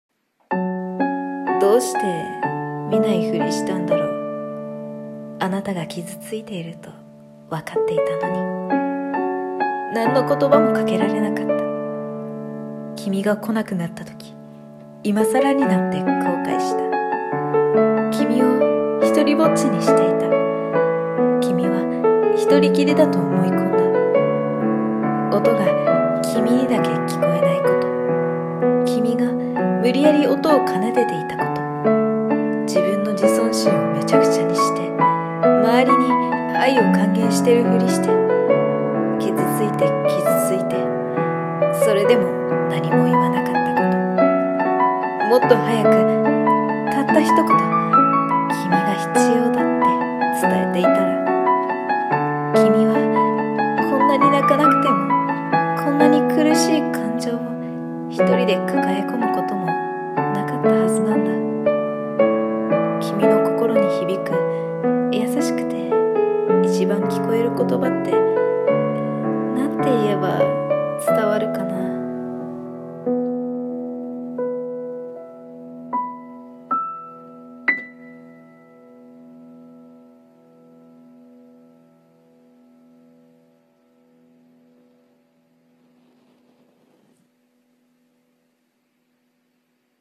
心に響く言葉【朗読